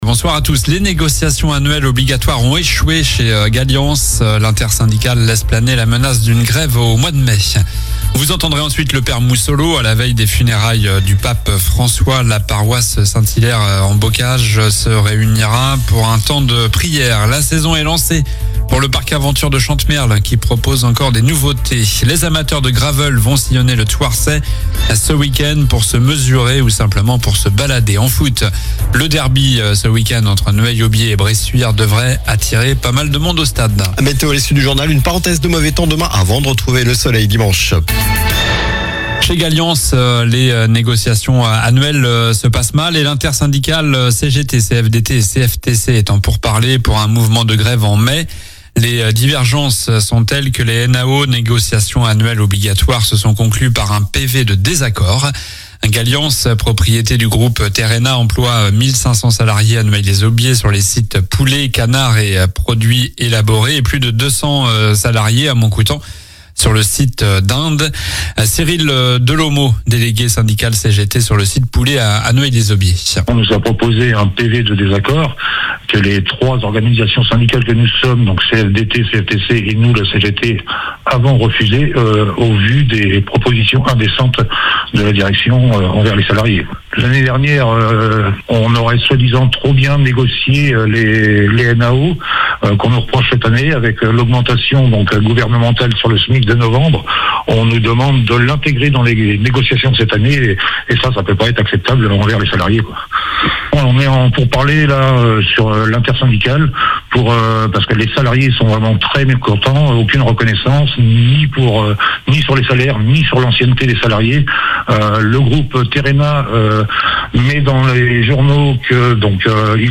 Journal du vendredi 25 avril (soir)